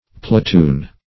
Platoon \Pla*toon"\, n. [F. peloton a ball of thread, a knot or